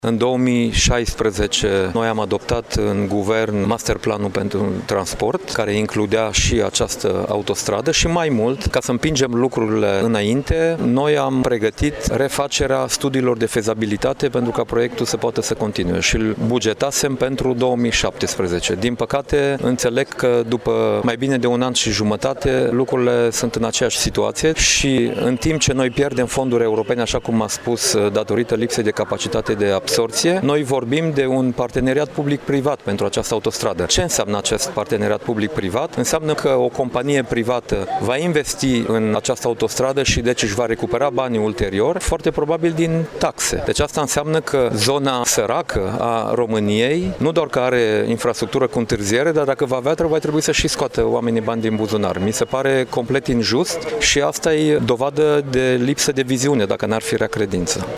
Afirmaţia a fost făcută astăzi, la Iaşi, de fostul premier Dacian Cioloş, care a participat la o dezbatere ce a avut drept temă „Relansarea economică şi soluţii pentru dezvoltarea Moldovei”.